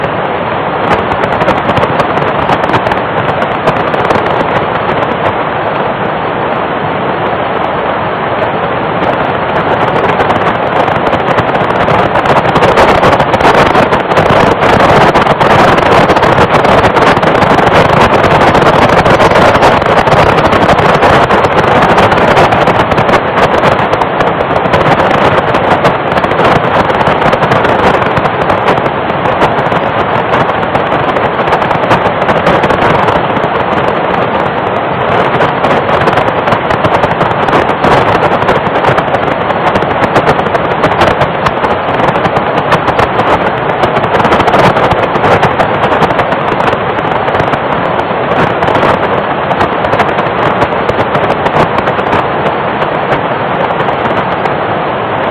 April 2, 2004 Io-B S-bursts 0425 UT 20.1 MHz CML III 147.12 Io Phase 085.87 Click below for sound file - 55 seconds To see and hear a 3.5 second detail of the dense bursts above click the image below